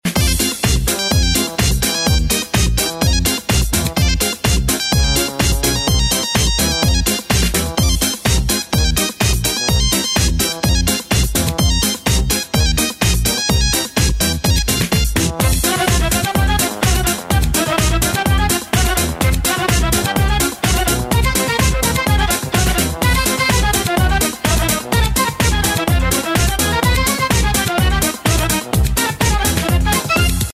• Качество: 128, Stereo
забавные
веселые
без слов
татарские